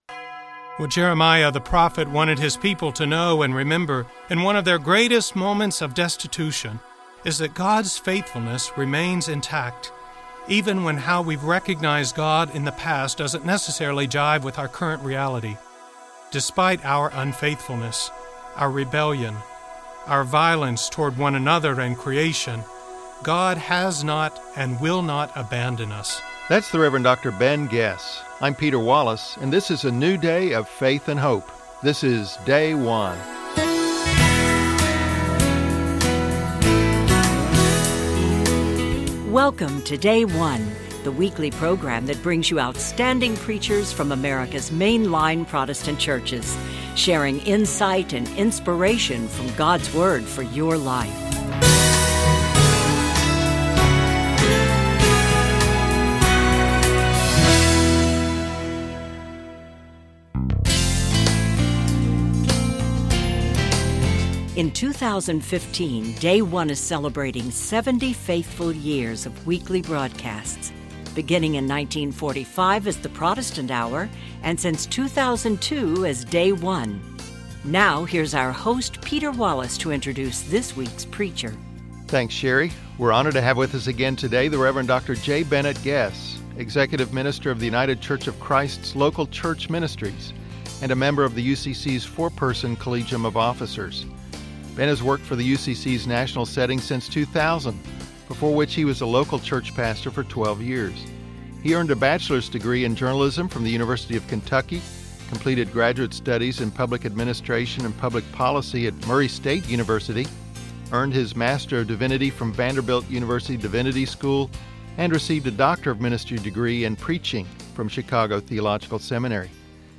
United Church of Christ 5th Sunday in Lent - Year B Jeremiah 31:31-34